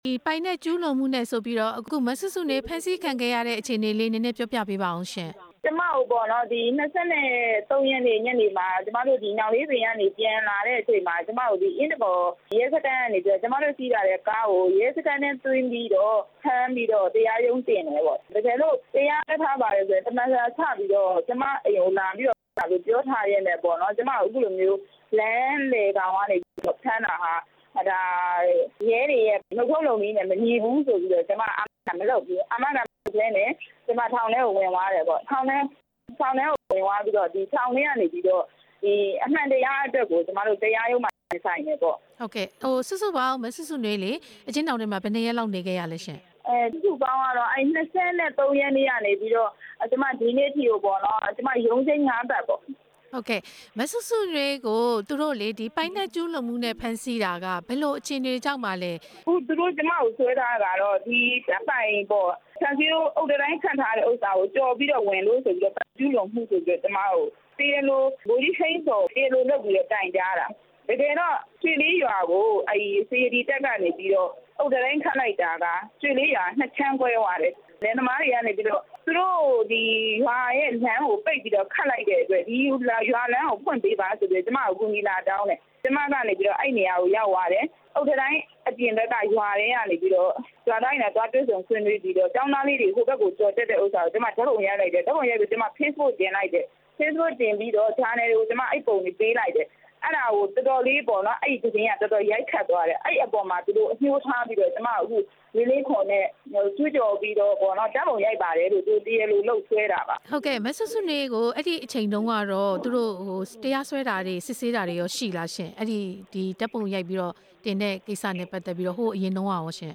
မစုစုနွေးကို ဆက်သွယ်မေးမြန်းချက်